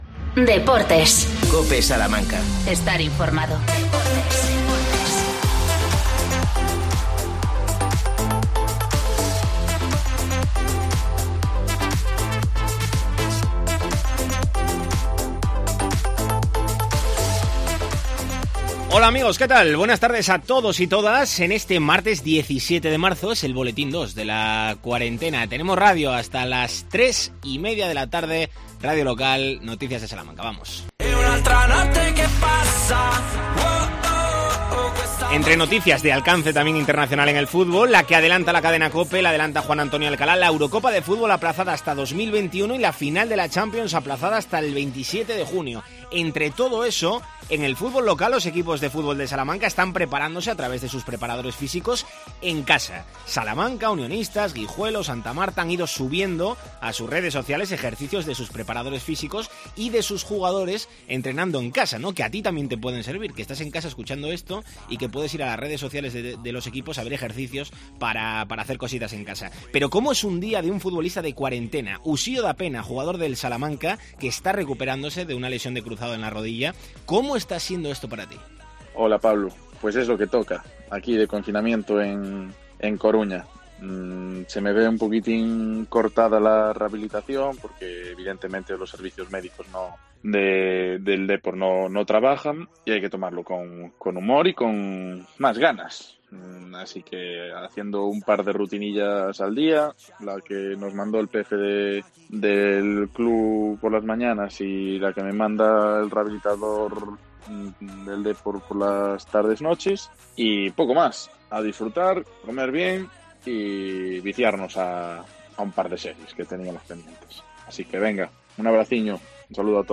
AUDIO: Boletín informativo.